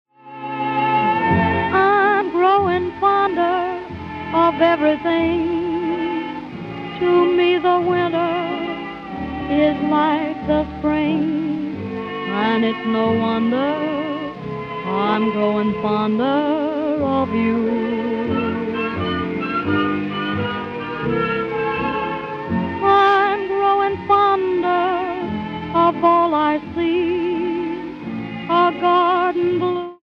Original recordings from 1931 - 1941, they're all winners.